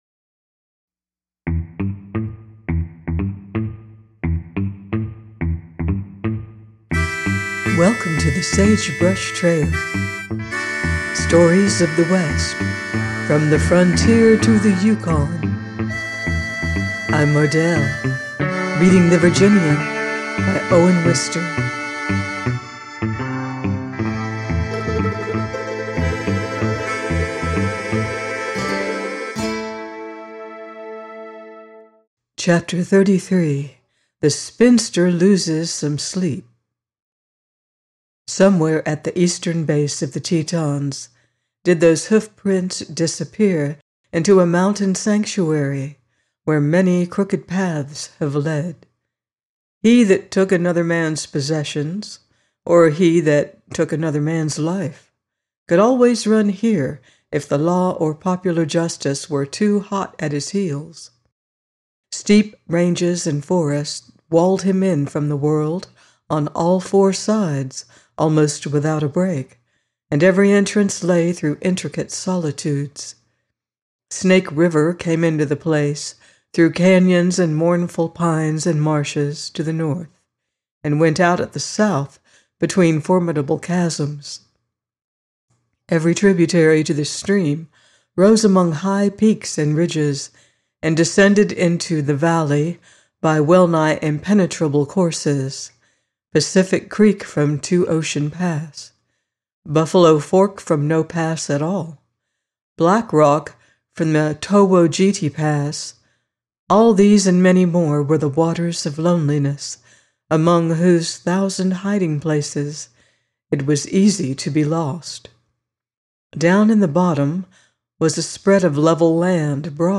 The Virginian - by Owen Wister - audiobook